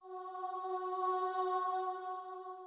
starting_note.mp3